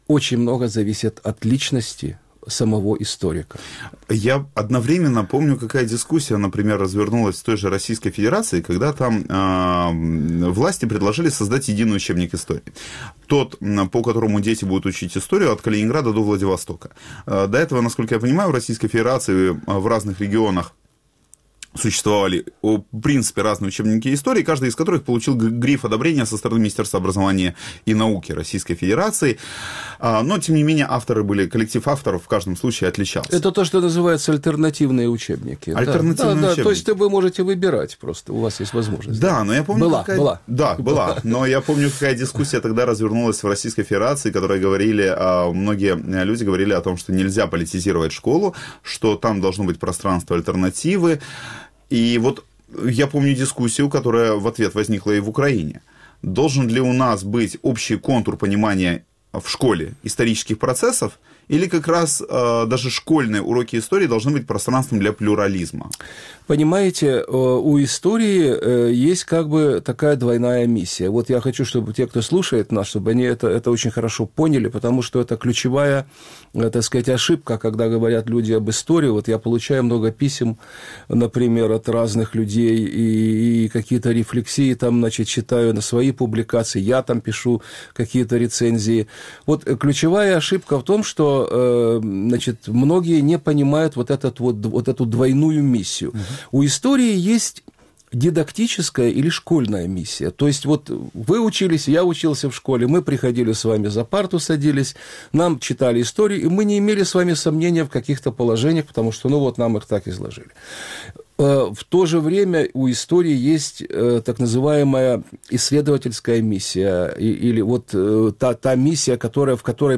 В вечернем эфире Радио Крым.Реалии говорят о целях и методах исторической науки. С какой целью историю используют в разных странах, можно ли рассчитывать на точность исторических учебников и чему должны учить на уроках истории?